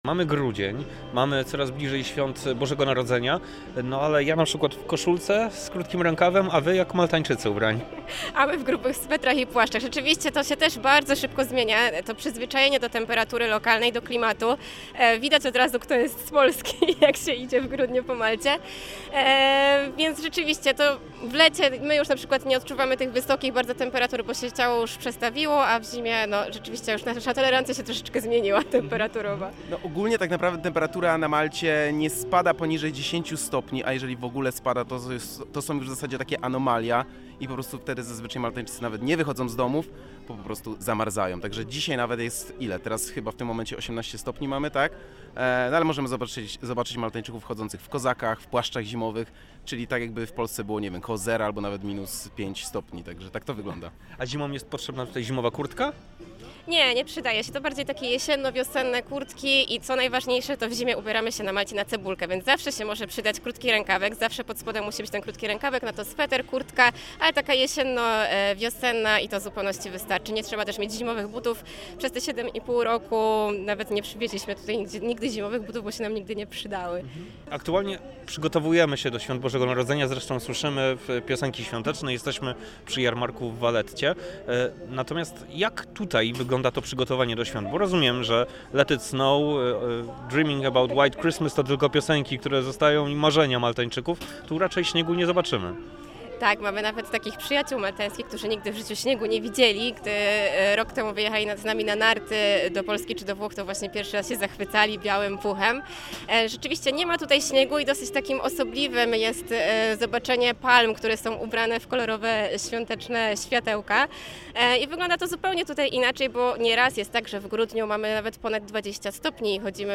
Posłuchajcie intrygujących wywiadów z ciekawymi ludźmi. Za mikrofonem dziennikarze RMF FM.